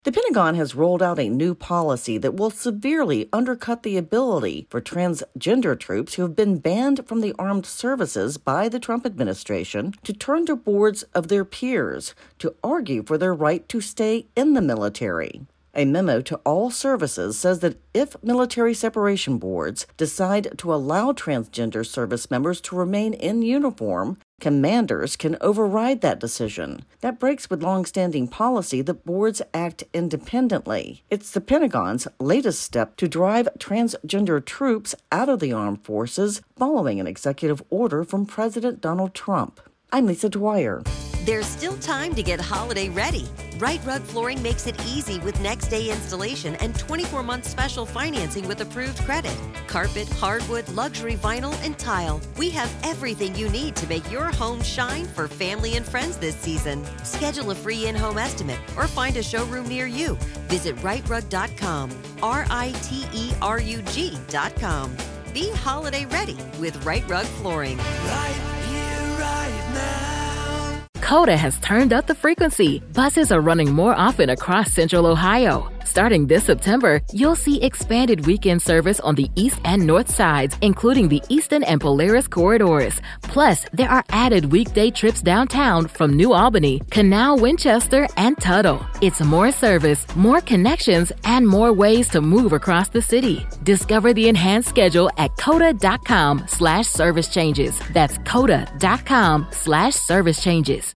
reports on the latest Pentagon policy that will affect a Transgender troop's ability to stay in the military.